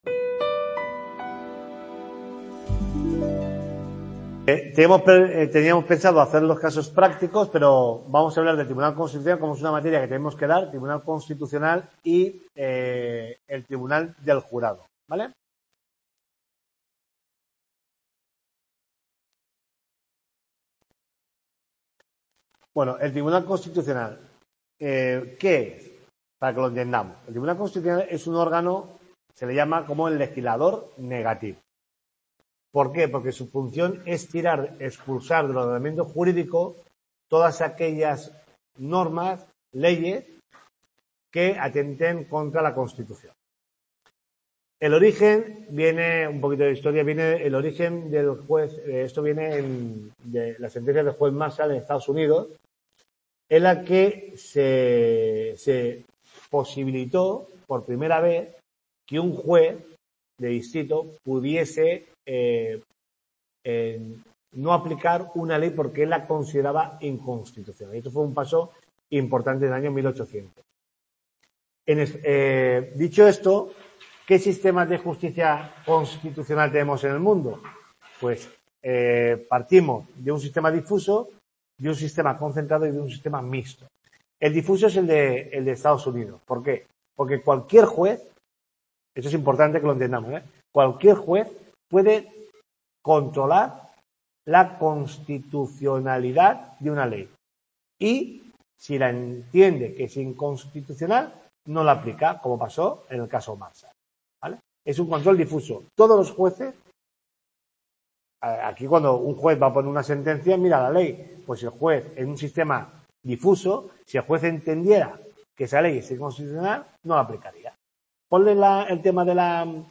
TUTORIA 6